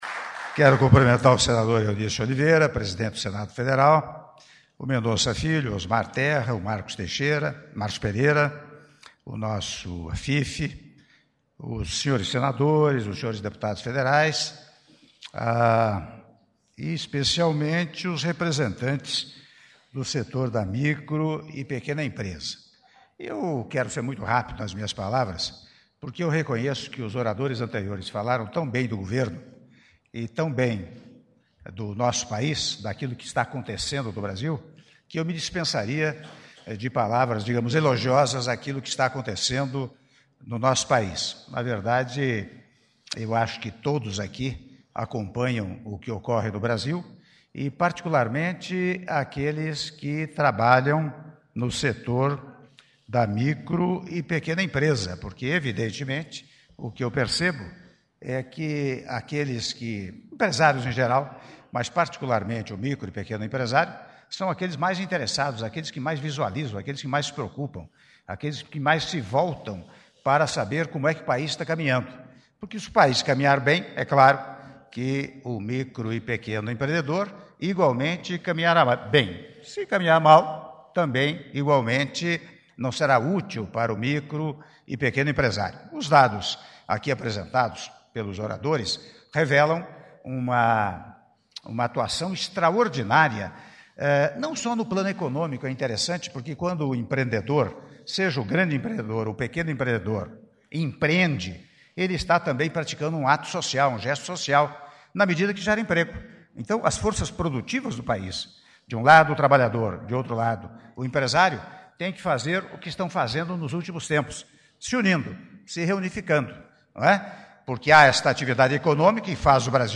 Áudio do discurso do Presidente da República, Michel Temer, na cerimônia Alusiva ao Dia Nacional da Micro e Pequena Empresa - Brasília/DF- (06min18s)